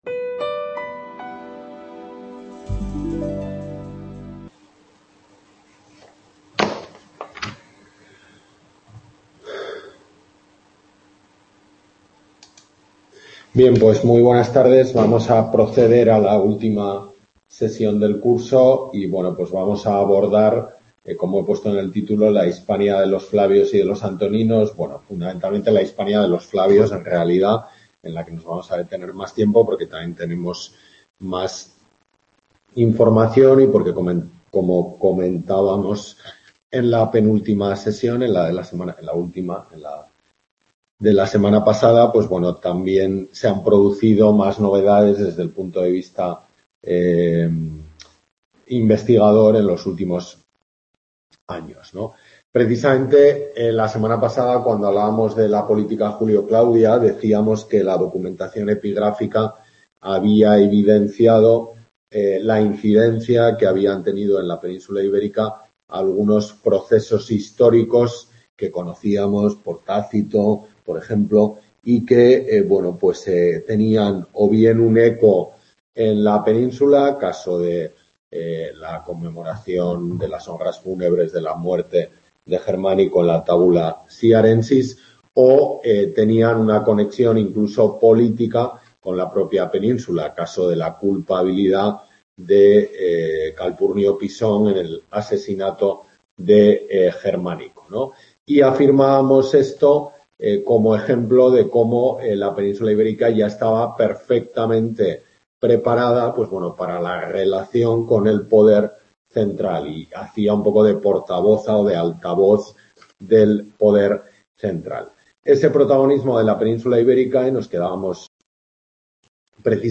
Tutoría de HAPI, UNED de Tudela